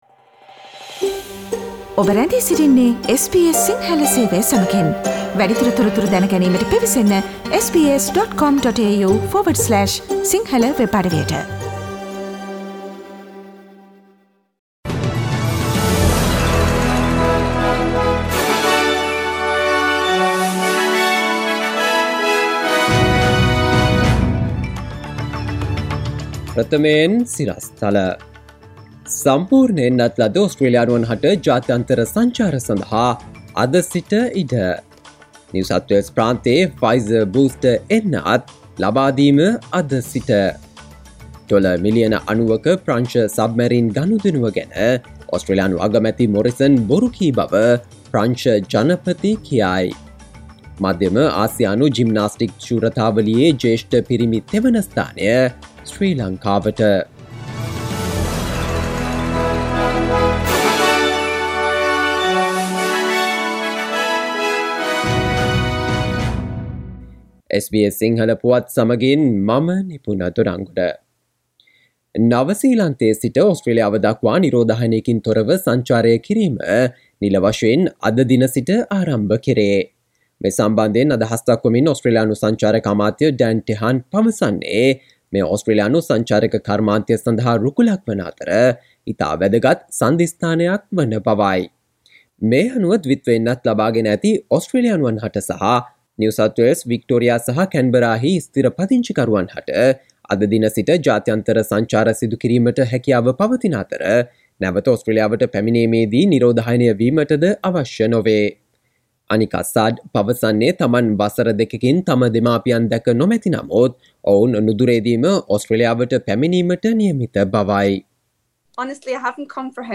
සවන්දෙන්න 2021 නොවැම්බර් 01 වන සඳුදා SBS සිංහල ගුවන්විදුලියේ ප්‍රවෘත්ති ප්‍රකාශයට...